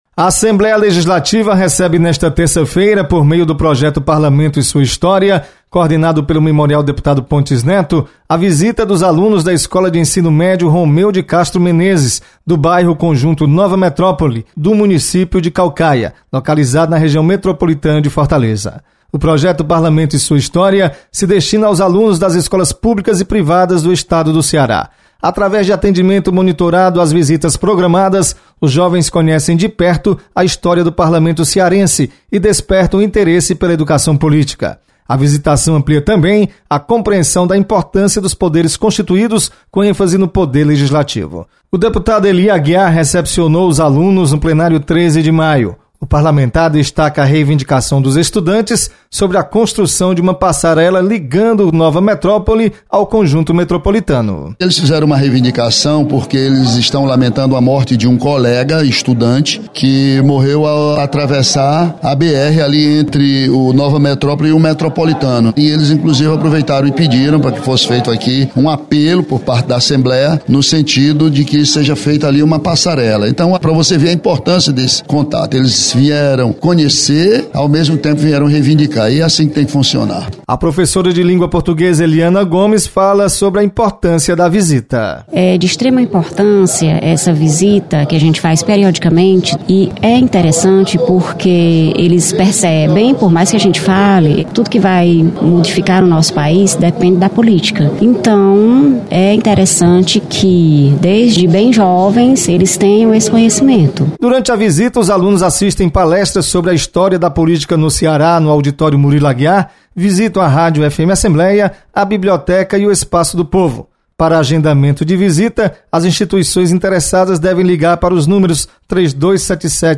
Você está aqui: Início Comunicação Rádio FM Assembleia Notícias Parlamento